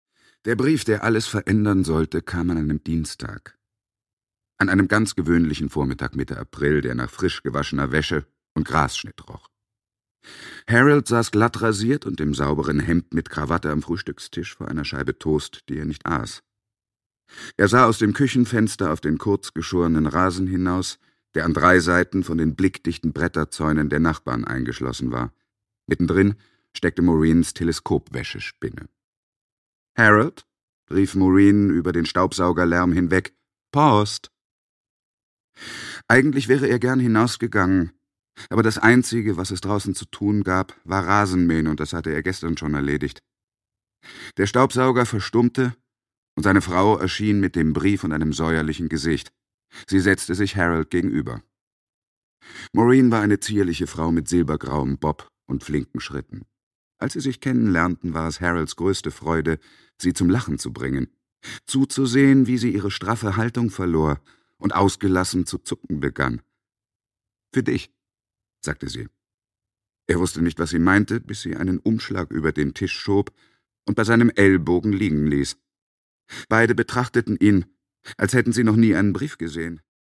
Produkttyp: Hörbuch-Download
Fassung: Autorisierte Lesefassung
Gelesen von: Heikko Deutschmann